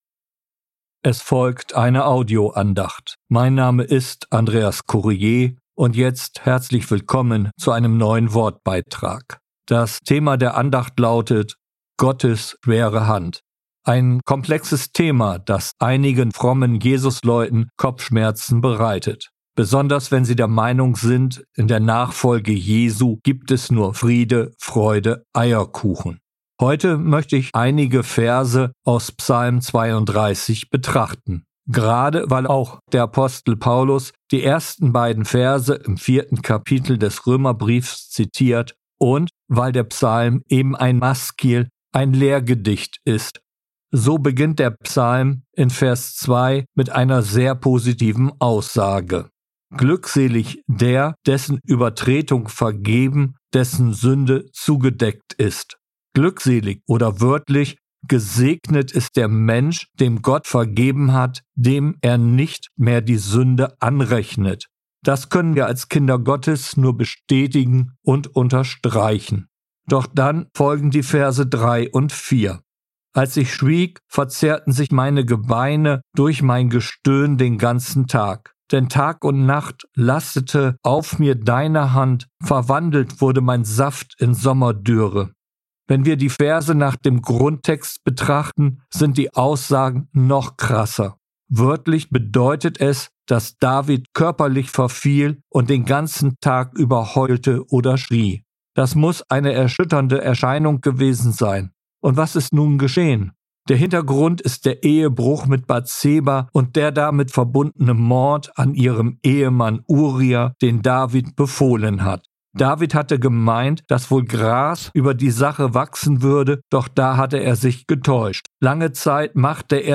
Gottes schwere Hand, eine Audioandacht